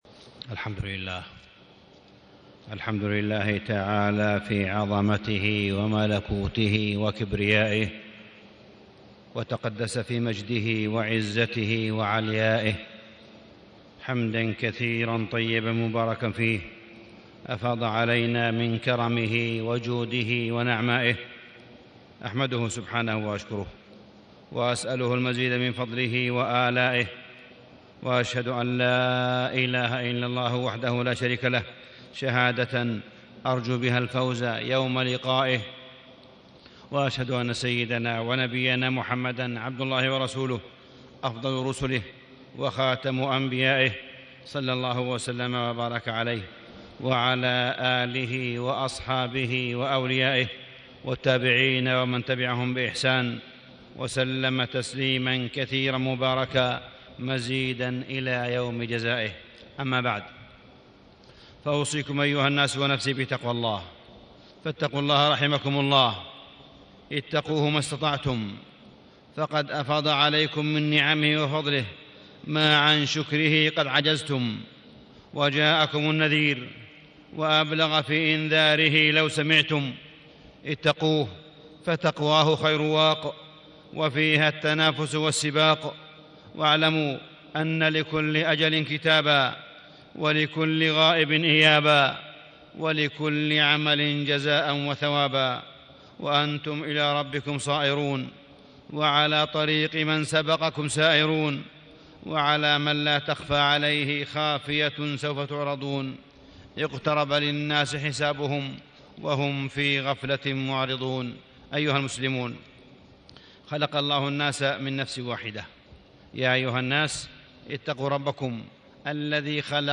تاريخ النشر ٢٩ جمادى الأولى ١٤٣٦ هـ المكان: المسجد الحرام الشيخ: معالي الشيخ أ.د. صالح بن عبدالله بن حميد معالي الشيخ أ.د. صالح بن عبدالله بن حميد إجابة نداء اليمن